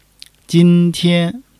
jin1--tian1.mp3